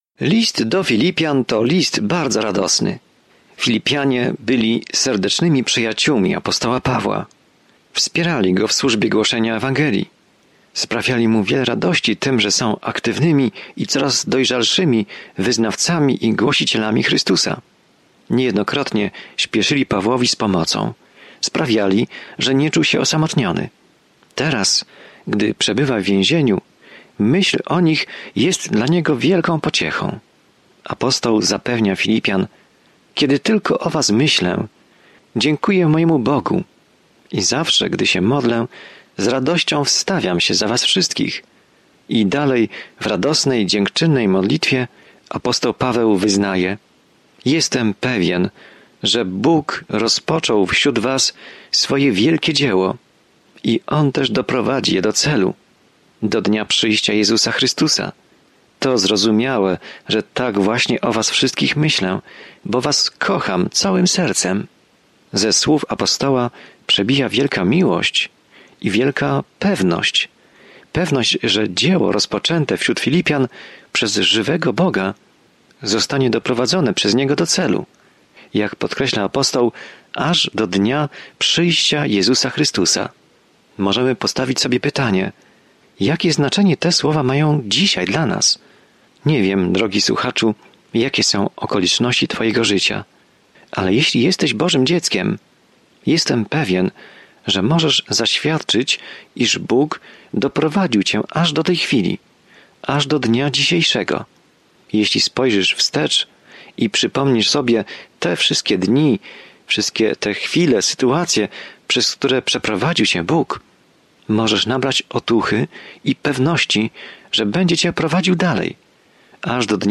Pismo Święte Filipian 1:9-14 Dzień 2 Rozpocznij ten plan Dzień 4 O tym planie To podziękowanie skierowane do Filipian daje im radosną perspektywę na trudne czasy, w których się znajdują, i zachęca ich, aby pokornie przez nie przejść razem. Codziennie podróżuj przez List do Filipian, słuchając studium audio i czytając wybrane wersety słowa Bożego.